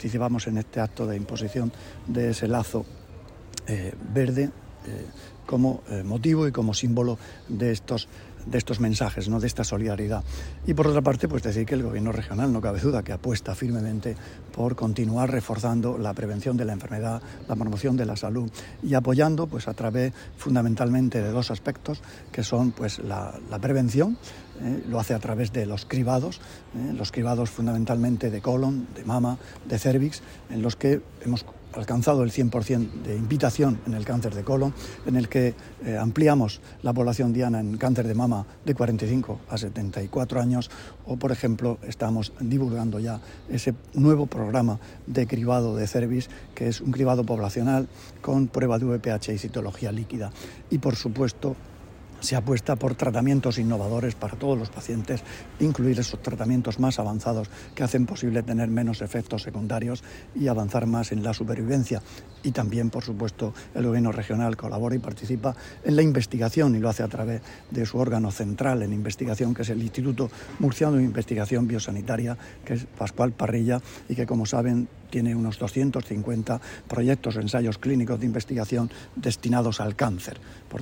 Declaraciones del consejero de Salud, Juan José Pedreño, durante su participación en la presentación de la campaña 'Brazalete de la Esperanza' de la Asociación Española Contra el Cáncer [mp3].